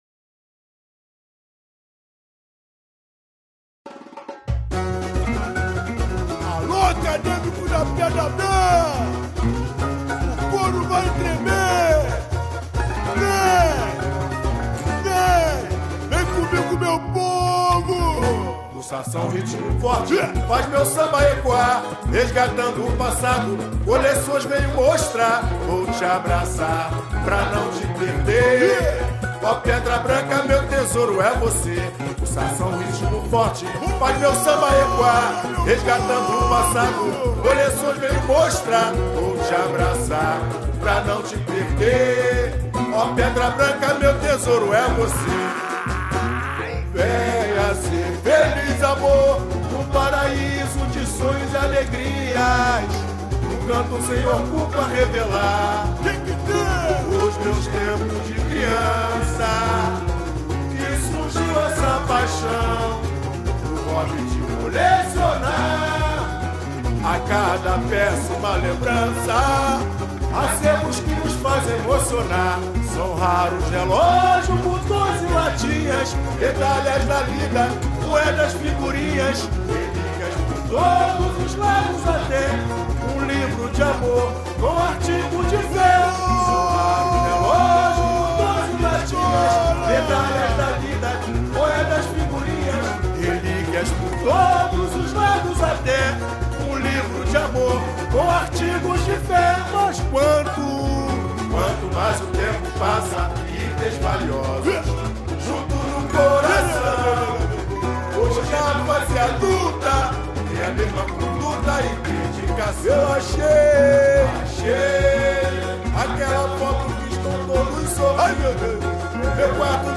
7 Cordas